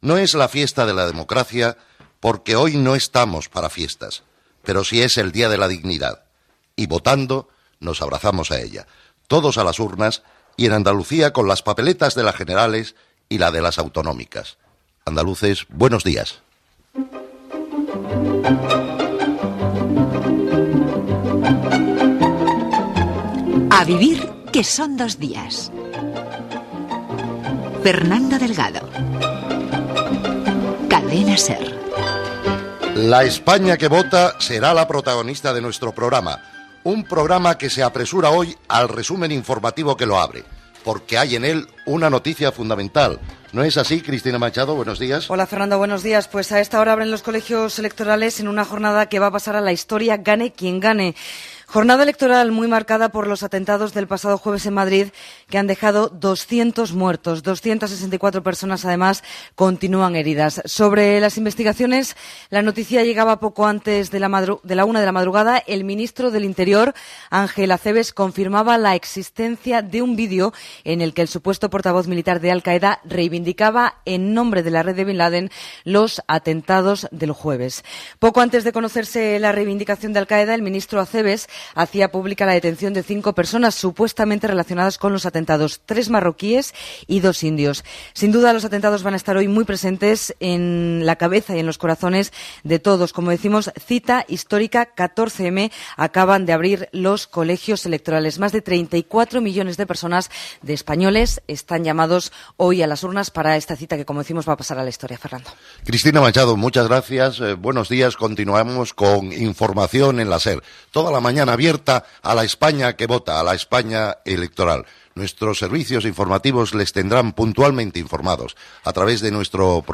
Comentari sobre l'abstenció en les eleccions. Espai publicitari. Promoció de "Carrusel deportivo", publicitat, indicatiu.
Informació de la jornada electoral des de Barcelona i Madrid.